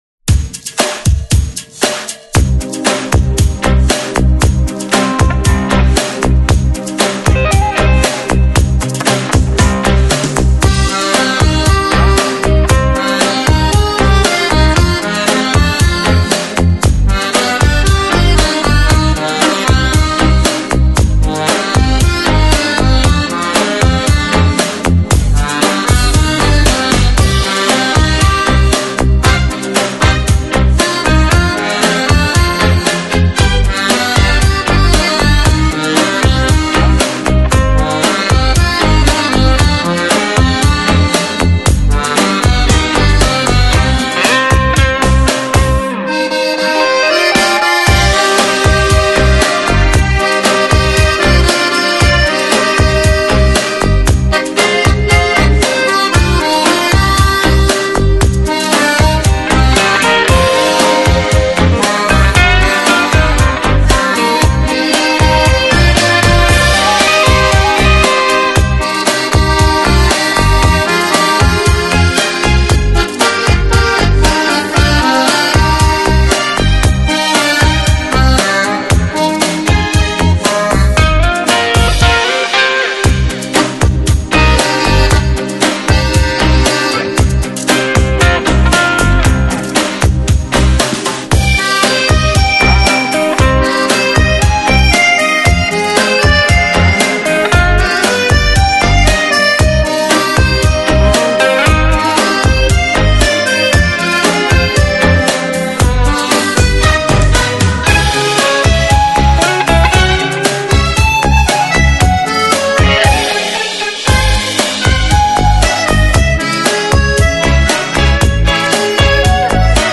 Жанр: Instrumental, Neo-Classical
一个由可爱的女孩组成的器乐乐队，以不同的风格和方向演奏器乐音乐。
小提琴和键盘、鼓、打击乐、吉他和手风琴、白俄罗斯琴和印度锡塔尔琴、中国胡卢西——乐器都是乐队在舞台上表演的音乐角色。